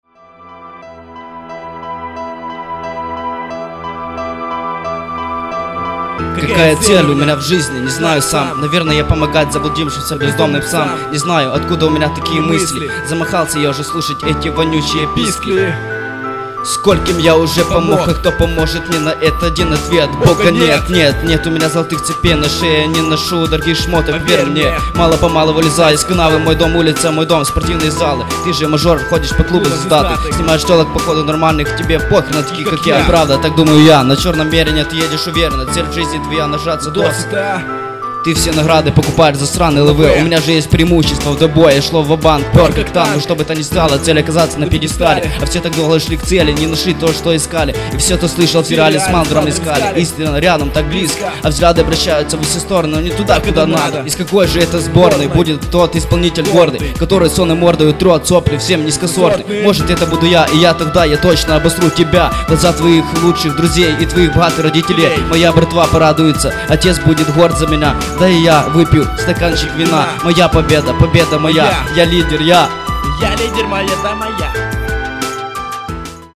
читка неуверенная